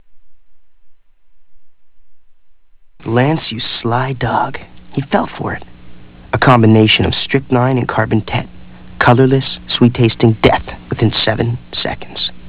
Click to hear Corey Haim